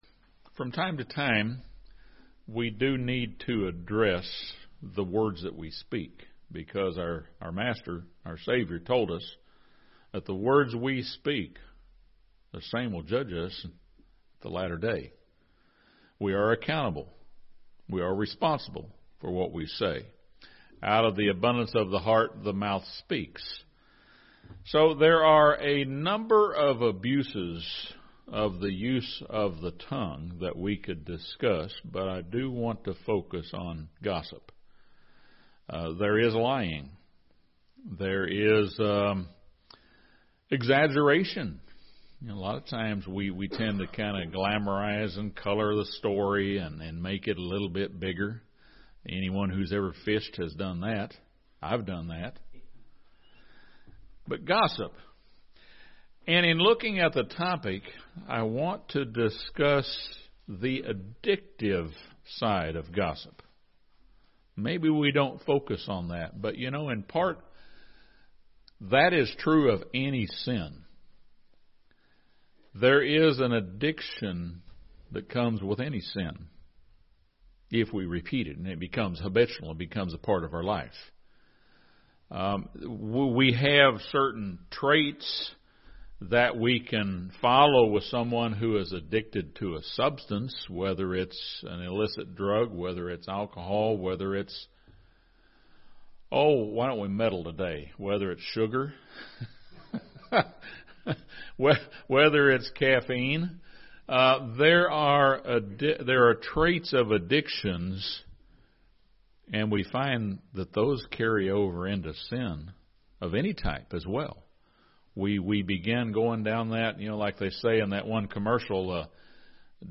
Satan, the father of lies, uses gossip to drive wedges between people. This sermon discusses the damage that can be done by gossip and offers suggestions for battling the addictive sin of gossip.